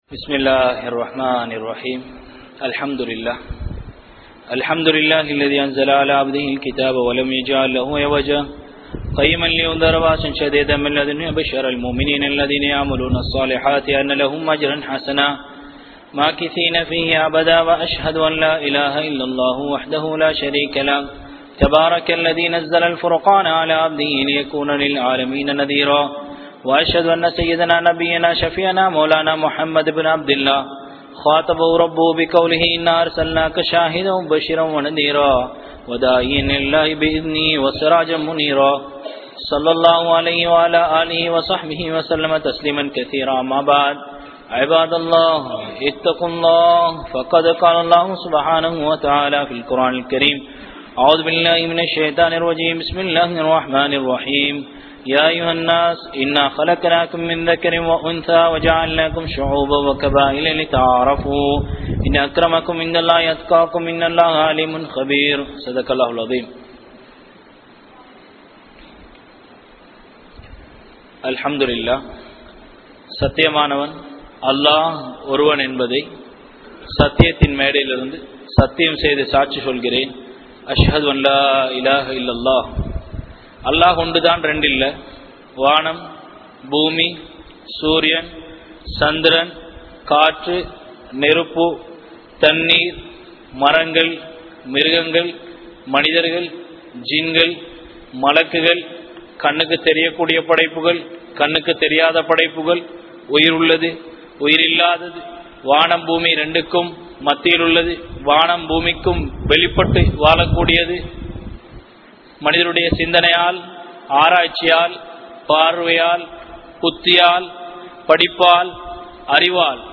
Maraikkapatta Ilangai Muslimkalin Varalaaru (மறைக்கப்பட்ட இலங்கை முஸ்லிம்களின் வரலாறு) | Audio Bayans | All Ceylon Muslim Youth Community | Addalaichenai
Kanampittya Masjithun Noor Jumua Masjith